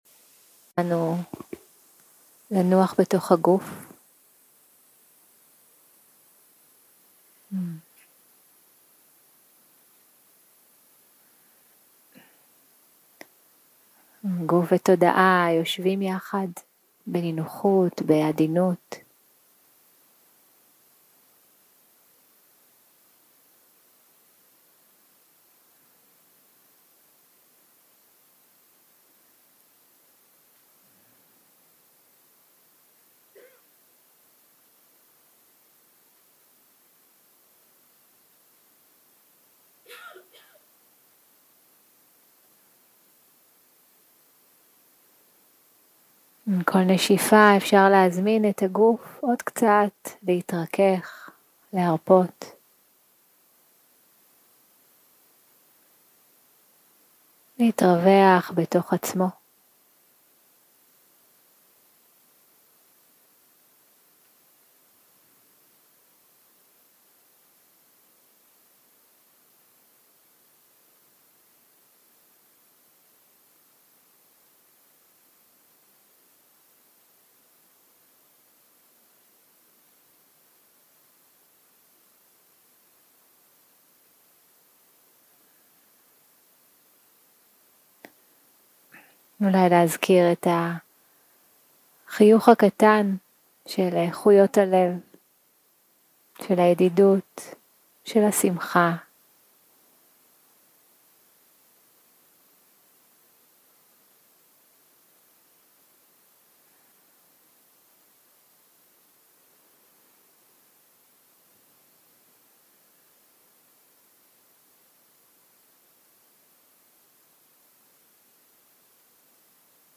מדיטציה מונחית